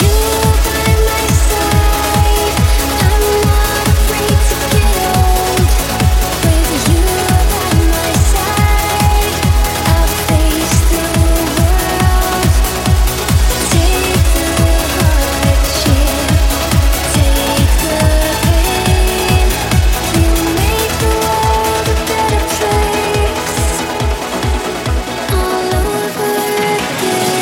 Genere: uplifting trance, (preascolto a 140bpm)